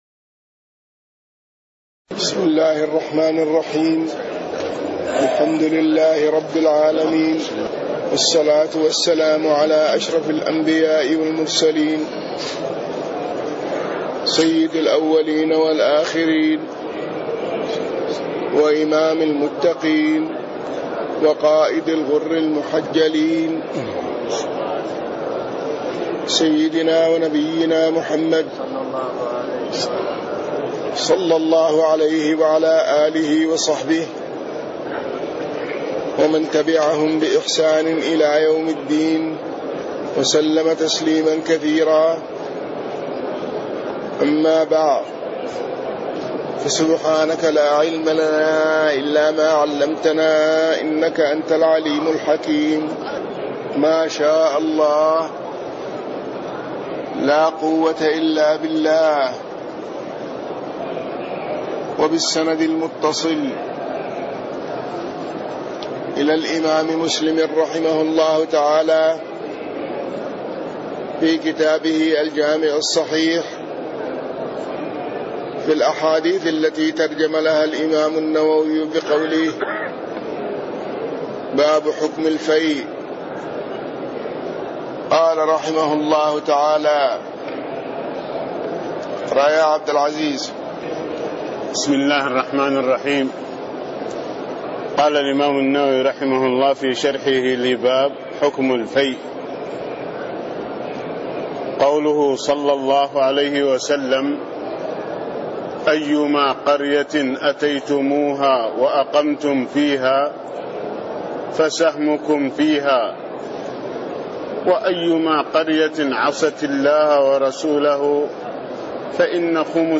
تاريخ النشر ١٦ رمضان ١٤٣٥ هـ المكان: المسجد النبوي الشيخ